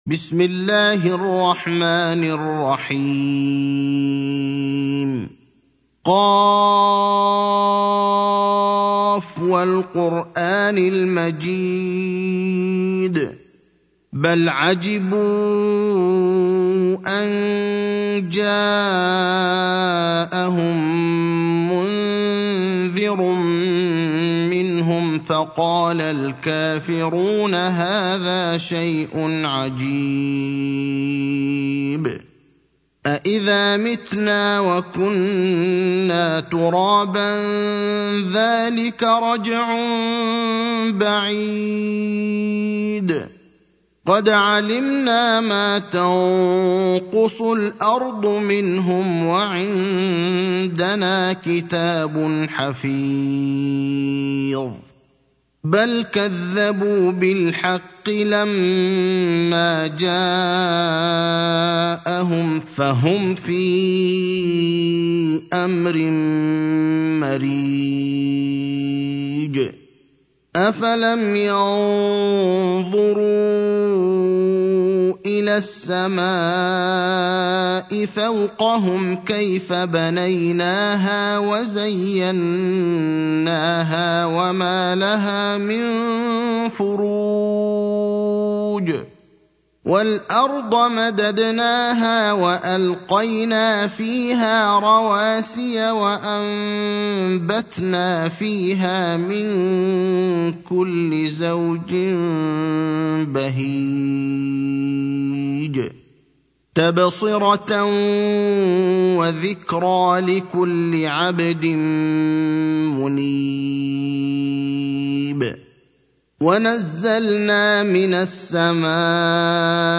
المكان: المسجد النبوي الشيخ: فضيلة الشيخ إبراهيم الأخضر فضيلة الشيخ إبراهيم الأخضر سورة ق